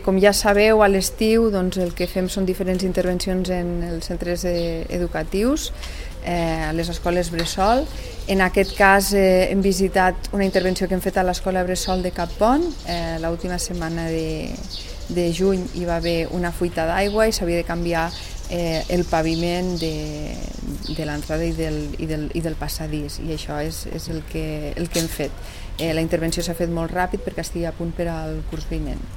tall-de-veu-de-lalcaldessa-accidental-sandra-castro-sobre-la-renovacio-del-paviment-a-leb-cappont